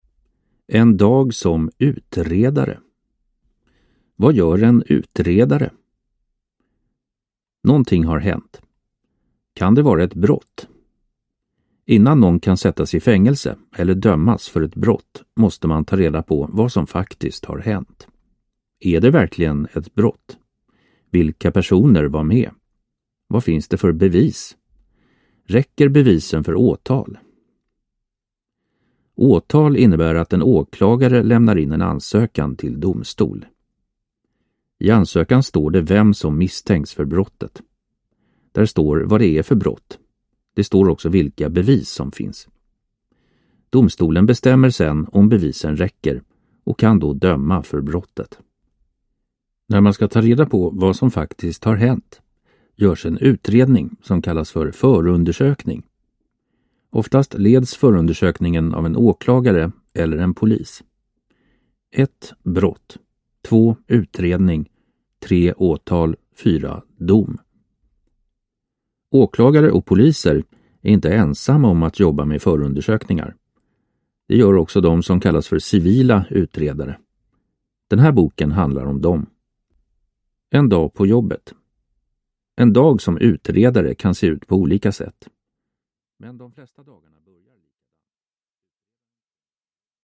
En dag som utredare – Ljudbok – Laddas ner